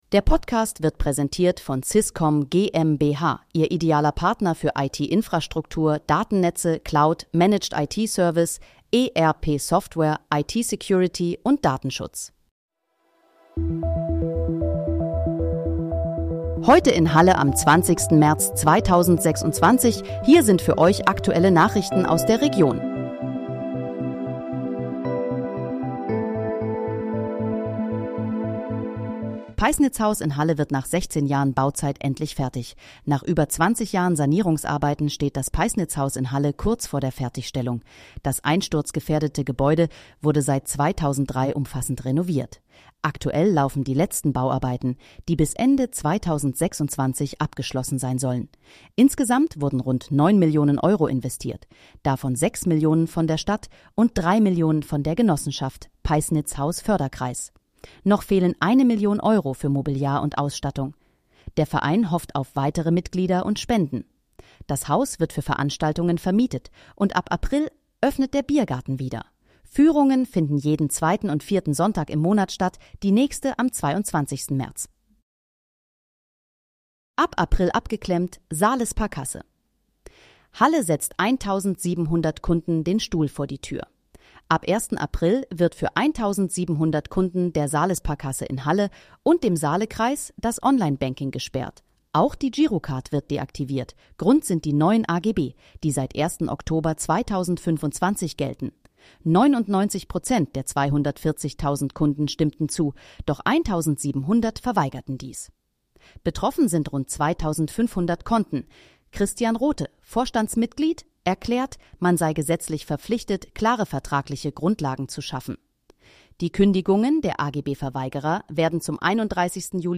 Heute in, Halle: Aktuelle Nachrichten vom 20.03.2026, erstellt mit KI-Unterstützung
Nachrichten